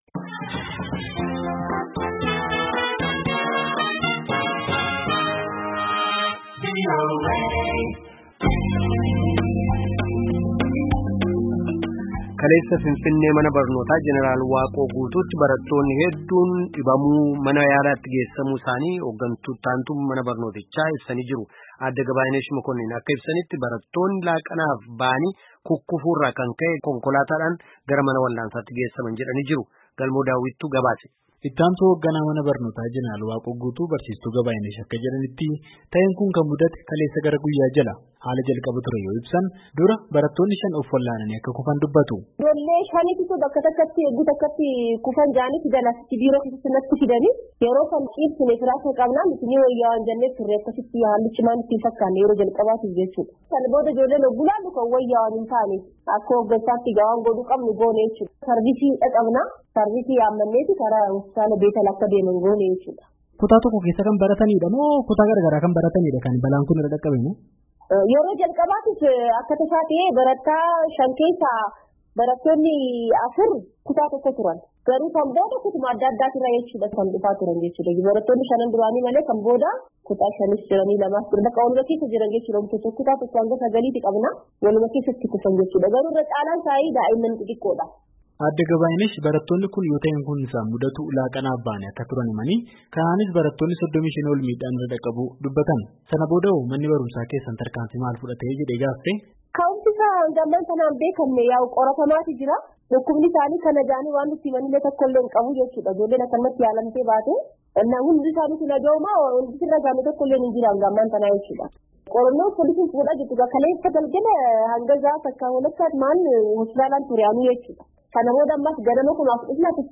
maatii barattootaa fi ogeessa fayyaa dubbisuun gabaasee jira.